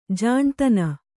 ♪ jāṇtana